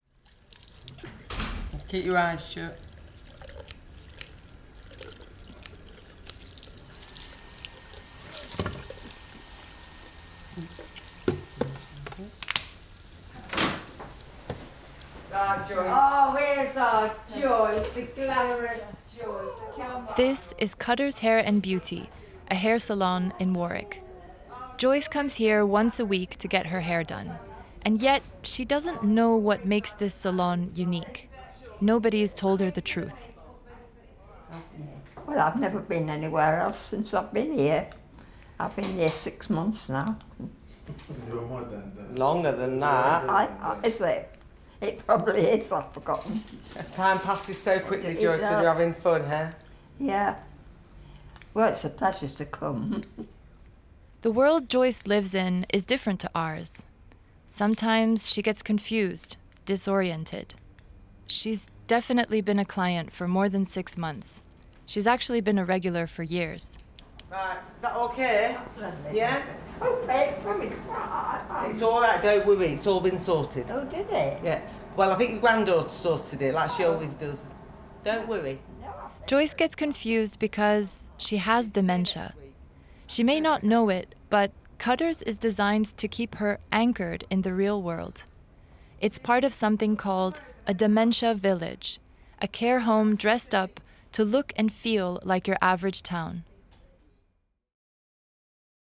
Experience the poignant reality of life in a Dementia Village with Am I Home?, a touching documentary on memory and identity.